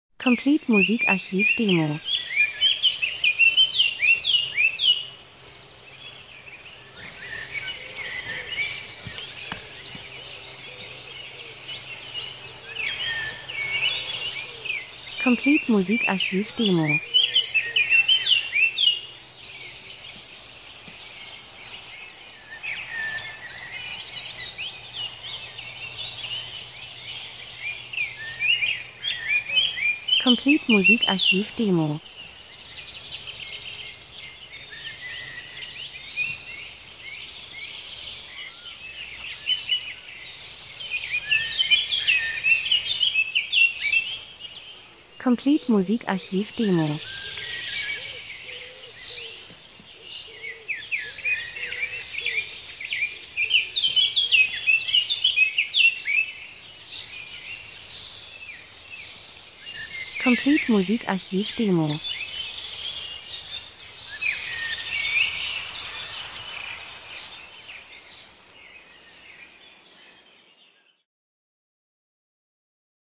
Sommer -Geräusche Soundeffekt Natur Wald Vögel 01:12